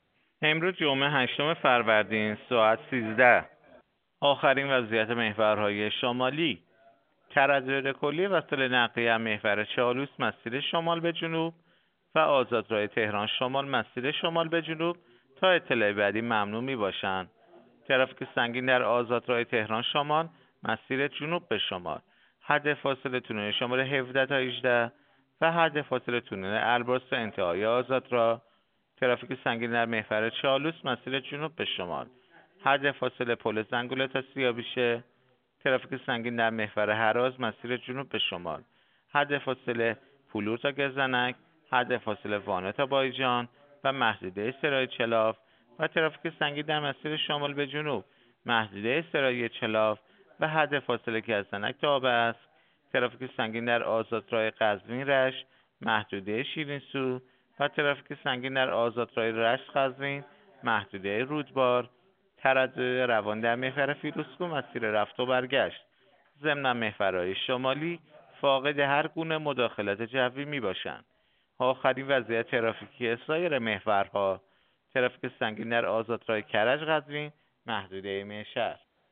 گزارش رادیو اینترنتی از آخرین وضعیت ترافیکی جاده‌ها ساعت ۱۳ هشتم فروردین؛